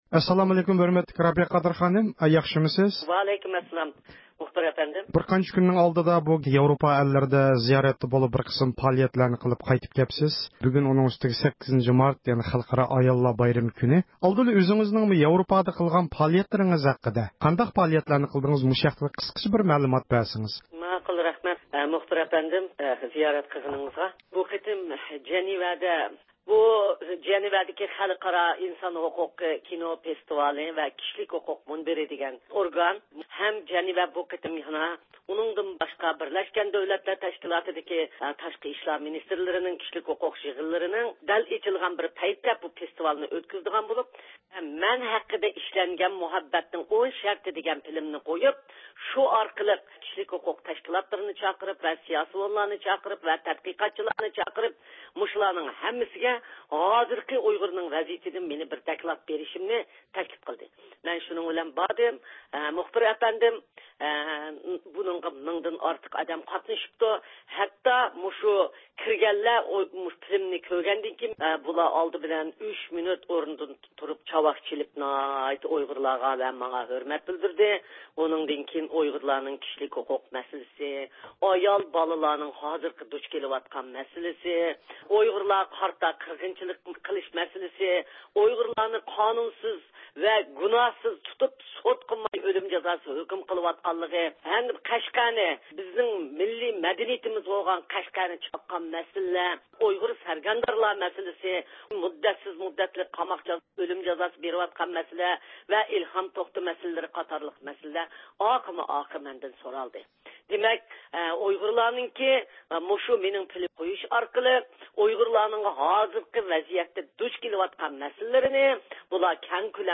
8 – مارت خەلقئارا ئاياللار بايرىمى دۇنيانىڭ ھەر قايسى جايلىرىدا ھەر خىل شەكىل ۋە ئۇسۇللار بىلەن خاتىرىلەنمەكتە. شۇ قاتاردا ھەر قايسى دۆلەتلەردىكى ئۇيغۇر ئاياللىرىمۇ ئۆز بايرىمىنى تۈرلۈك شەكىلدە خاتىرىلىدى. بۇ مۇناسىۋەت بىلەن تېخى يېقىندىلا جەنۋەدىكى 13 – نۆۋەتلىك خەلقئارالىق كىشىلىك ھوقۇق كىنو فېستىۋالى ۋە مۇنبىرىگە قاتنىشىپ، ئۇيغۇرلار دۇچ كېلىۋاتقان تۈرلۈك مەسىلىلەر ھەققىدە دوكلات بېرىپ، مۇھىم نەتىجىلەرگە ئېرىشكەنلىكىنى قەيت قىلغان دۇنيا ئۇيغۇر قۇرۇلتىيى رەئىسى رابىيە قادىر خانىم بۈگۈن 8 – مارت كۈنى رادىئومىز زىيارىتىنى قوبۇل قىلىپ، 8 – مارت ئاياللار بايرىمى مۇناسىۋىتى بىلەن پۈتۈن دۇنيادىكى ئاياللارنى جۈملىدىن ئۇيغۇر ئاياللىرىنى بايرام بىلەن تەبرىكلىدى، ئۇ سۆزىدە ئۇيغۇر ئېلىدە ئۆزلىرىنىڭ دىنىي، مىللىي، كىشىلىك ۋە باشقا تۈرلۈك ھوقۇقلىرى ھەم ئەركىنلىكى يولىدا تۈرمىلەردە يېتىۋاتقان، تۈرلۈك بېسىم – زۇلۇملارغا يولۇقۇپ، ئازاب چېكىۋاتقان ئۇيغۇر خانىم – قىزلىرىغا بولغان ئىزگۈ – تىلەكلىرىنى ئىزھار قىلدى.